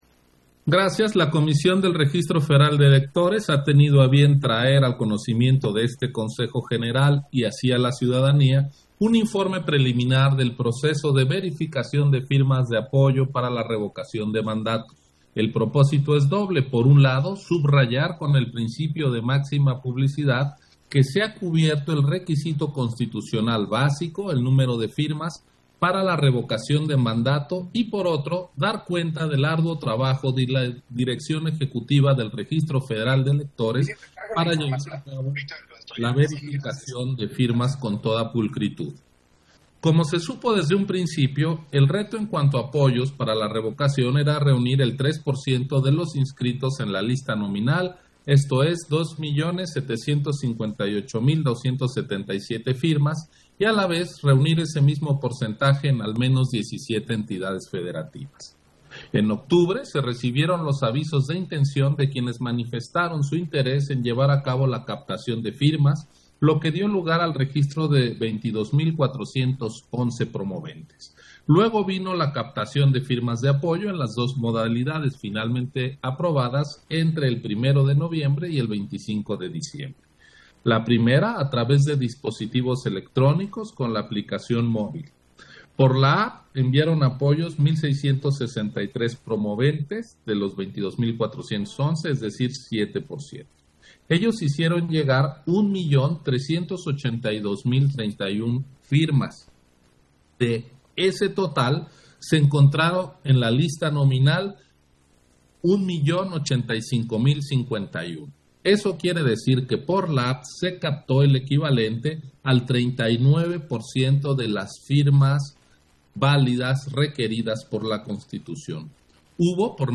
Intervención de Ciro Murayama, en Sesión Extraordinaria, relativo al informe del proceso de verificación del porcentaje de firmas de apoyo a la Revocación de Mandato